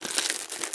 vending2.ogg